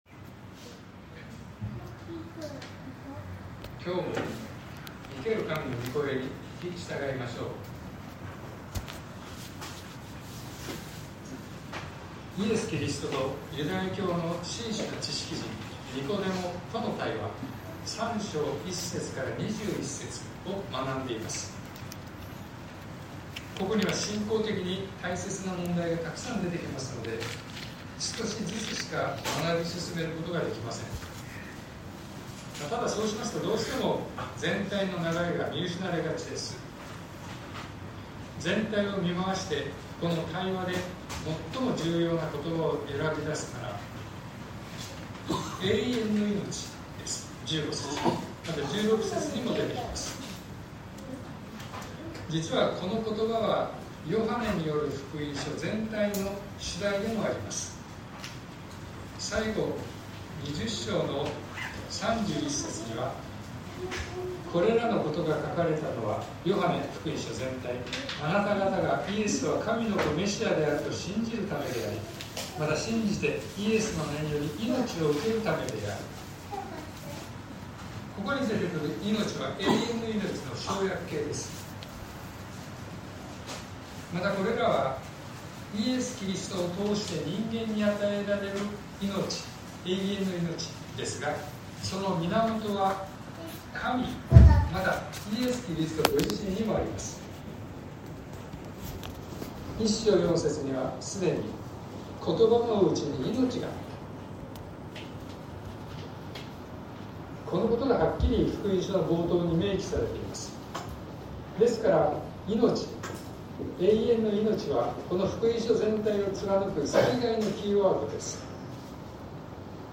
2025年07月13日朝の礼拝「天にのぼった者」東京教会
東京教会。説教アーカイブ。